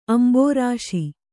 ♪ ambōrāśi